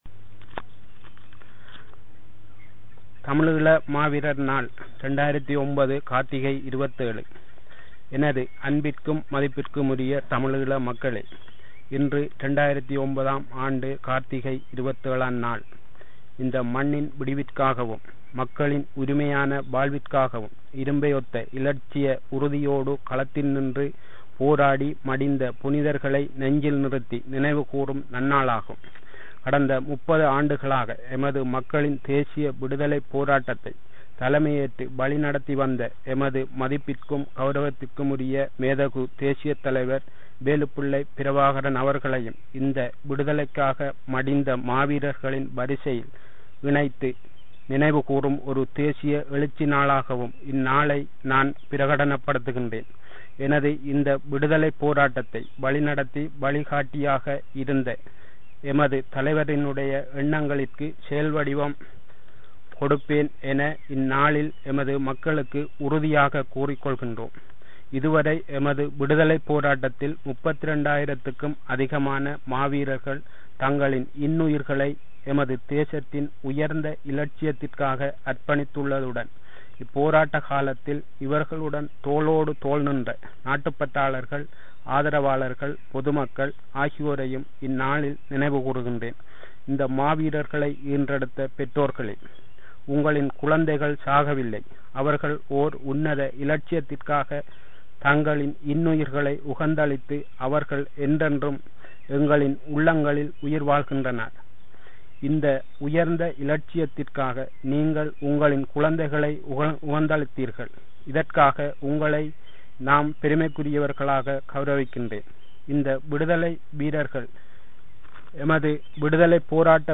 Maaveerar_Speech_2009_Voice.mp3